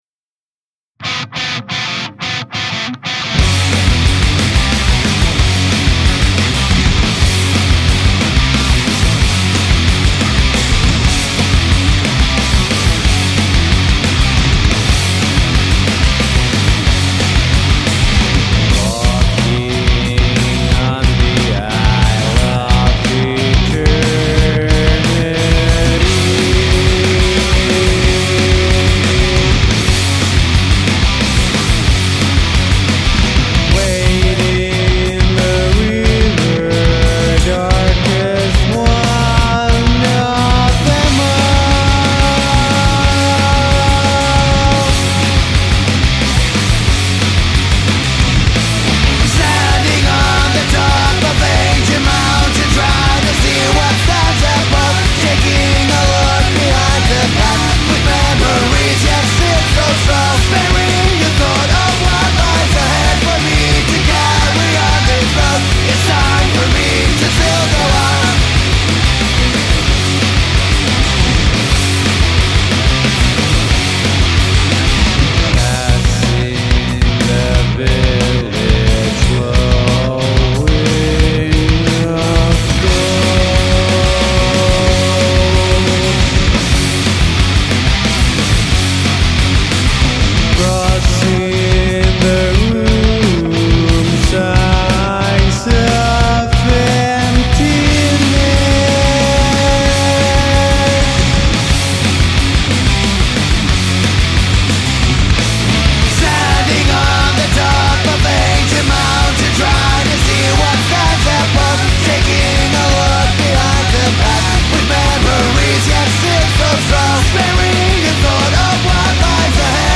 Recorded in OUBS Studios, Espoo in Dec 02nd - 06th of 2005